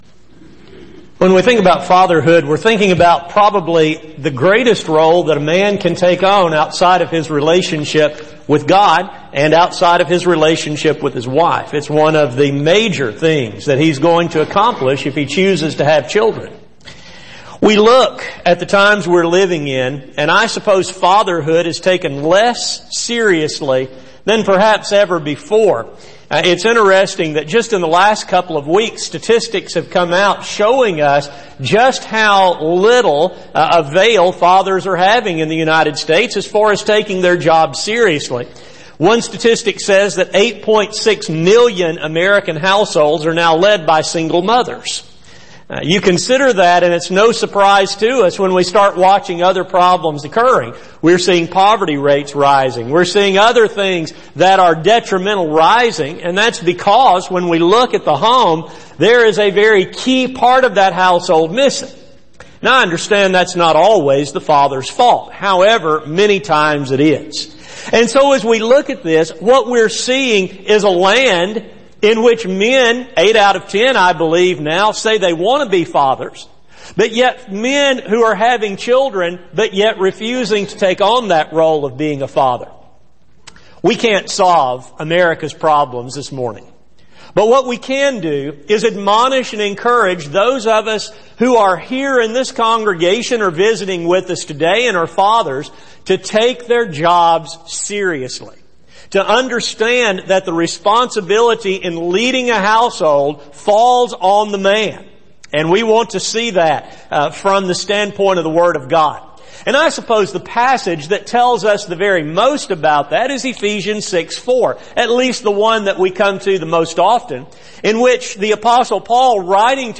Series: Take Time To Be Holy Service: Sun AM Type: Sermon